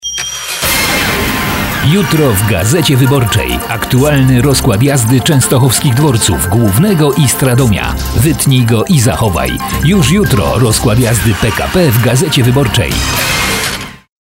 Professioneller polnischer Sprecher für TV / Rundfunk / Industrie.
Kein Dialekt
Sprechprobe: eLearning (Muttersprache):
polish voice over artist